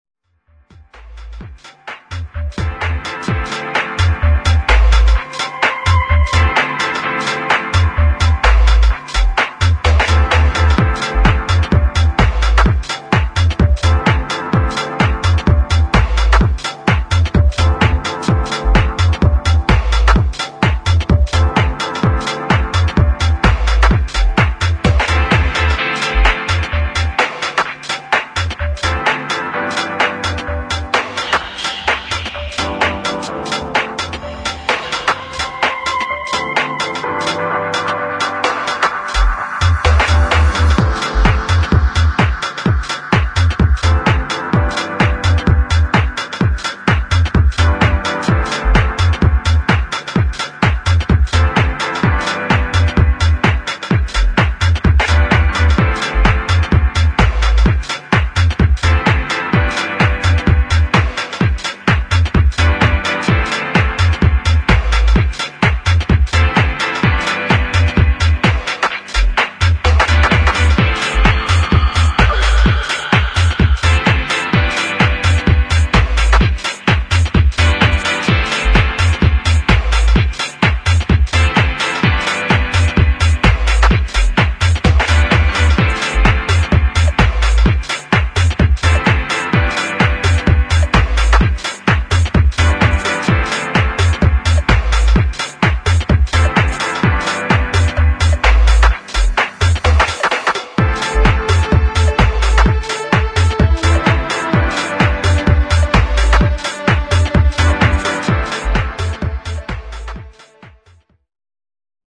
[ TECHNO / UK GARAGE / BASS ]